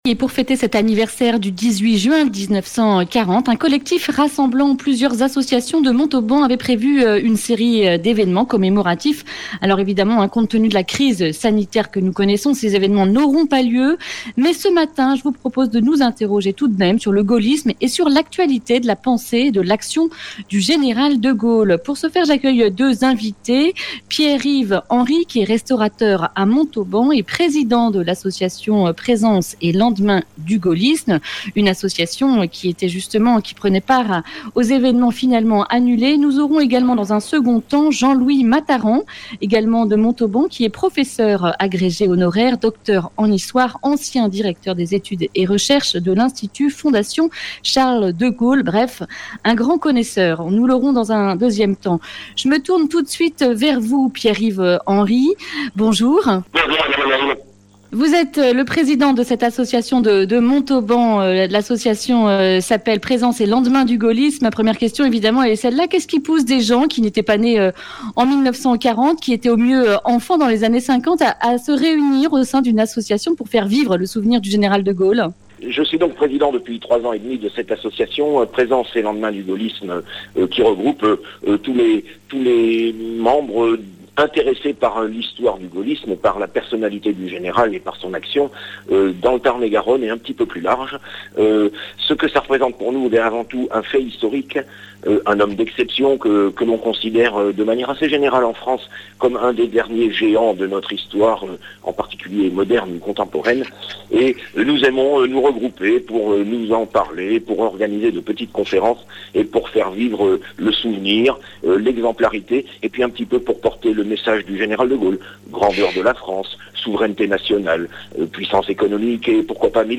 Accueil \ Emissions \ Information \ Régionale \ Le grand entretien \ En quoi De Gaulle nous inspire-t-il aujourd’hui ?